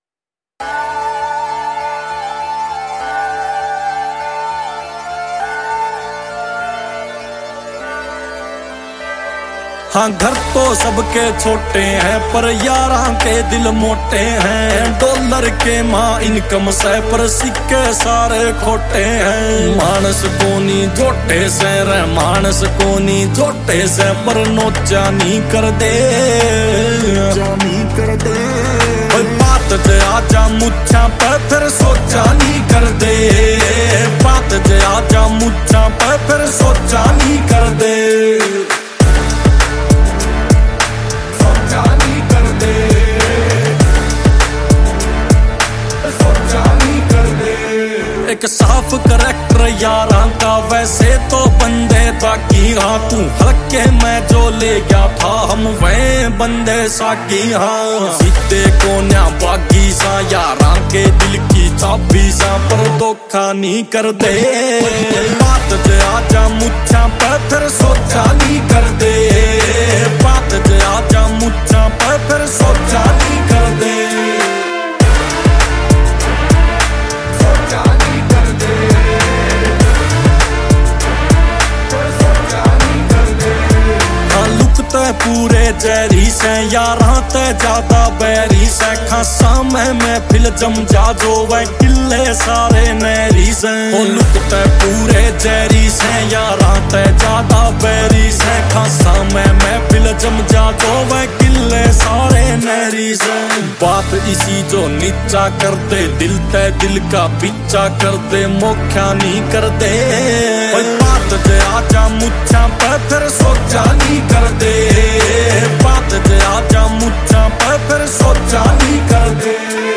Category: Haryanvi Songs